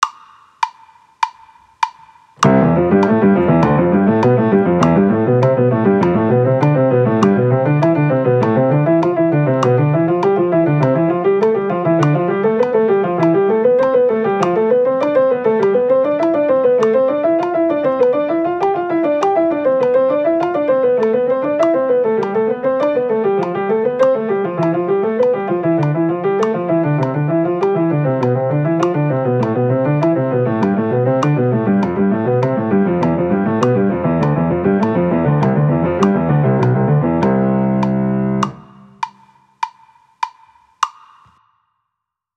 まずは楽譜通りに弾いてみよう！
音声の演奏は、メトロノームを ♩＝100に合わせていますが、自分の弾きやすいテンポでOK。